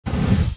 flamethrower1.wav